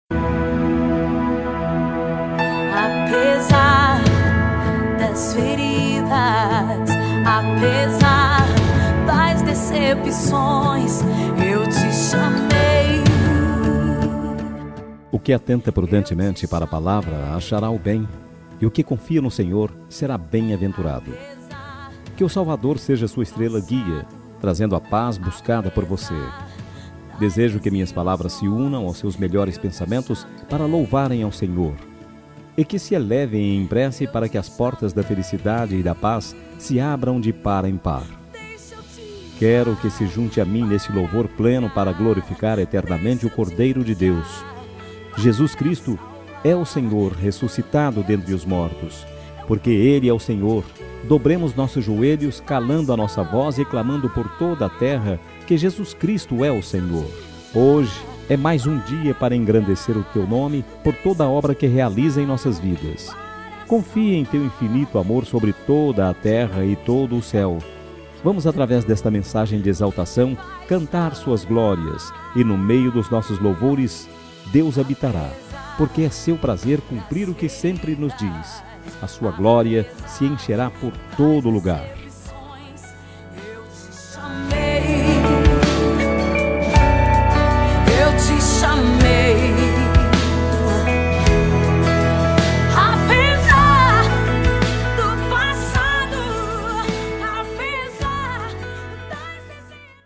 NEUTRA EVANGÉLICA
Voz Masculina